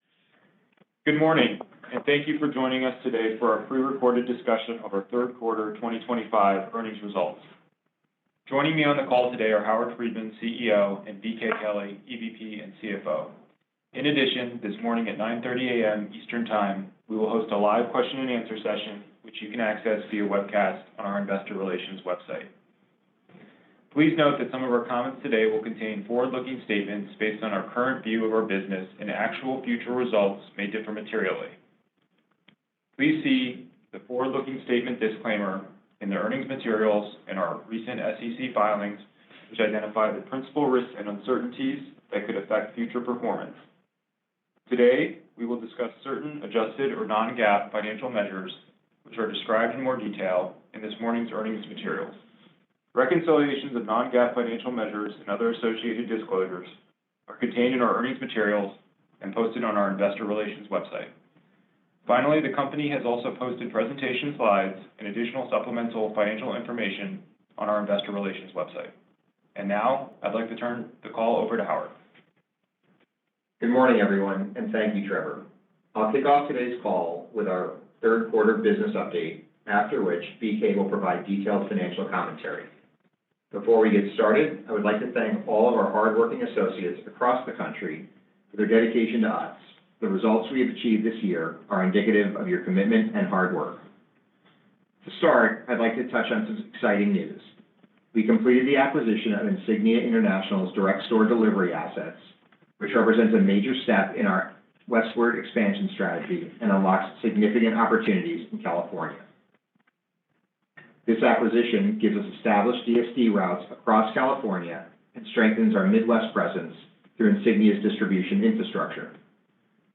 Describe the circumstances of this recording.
Pre-Record_-Utz-Brands-Inc-Third-Quarter-2025-Earnings-Conference-Call-1.mp3